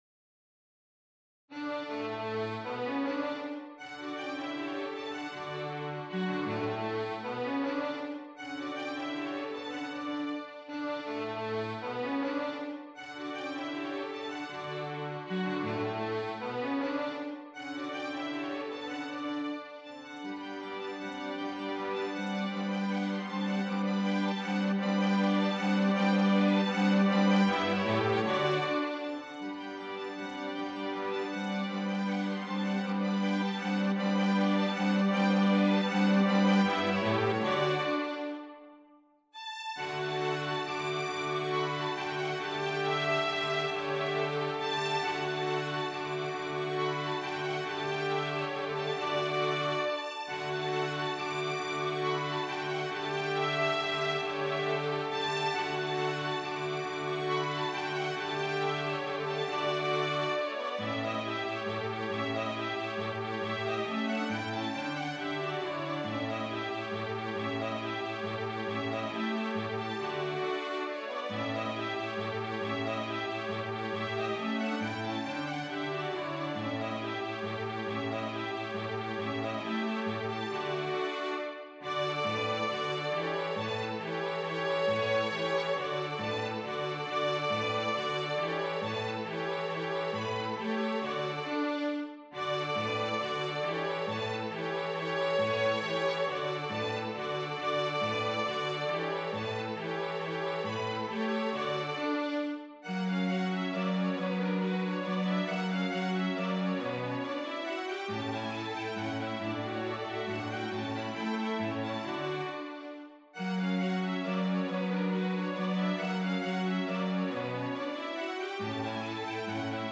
For 2 Violins and Cello mp3 (4 923 Ko)